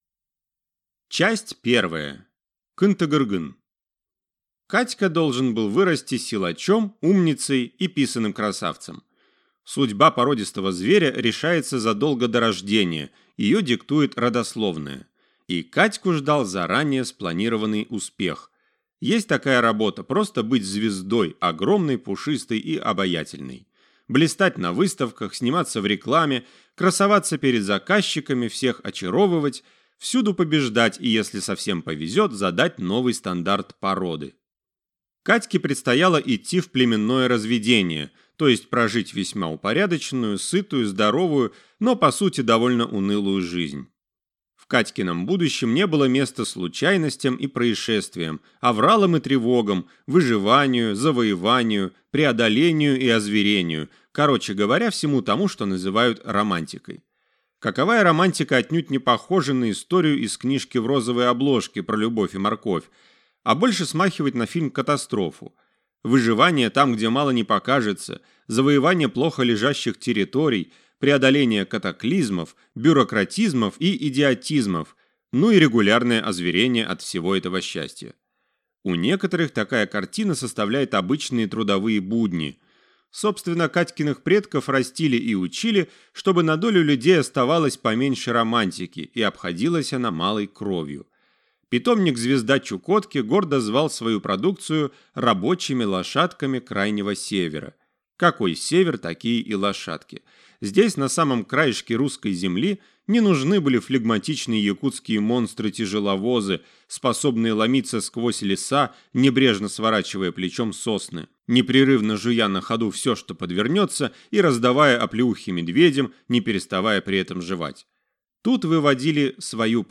Аудиокнига Родина слонов | Библиотека аудиокниг